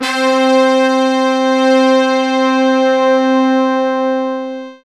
SI2 BRASS02R.wav